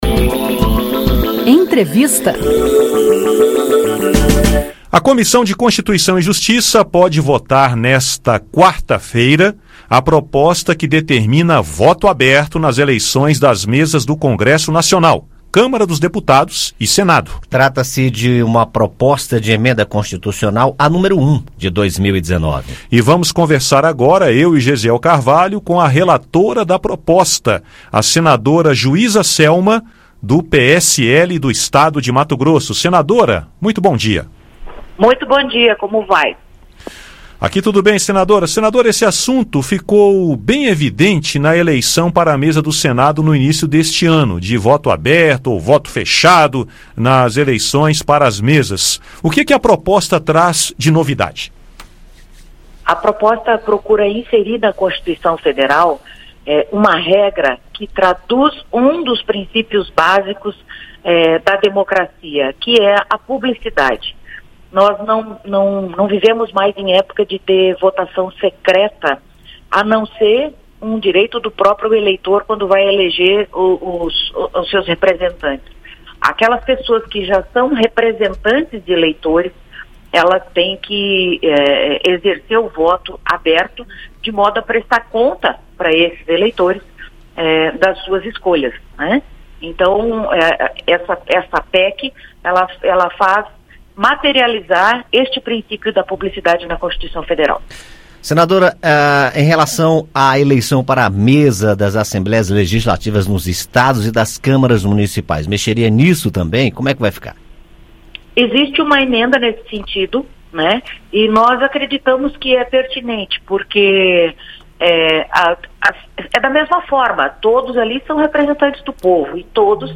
Segundo a relatora da proposta, senadora Juíza Selma (PLS – MT), os eleitores têm o direito de saber como votam os seus representantes no Congresso Nacional. Ouça o áudio com a entrevista.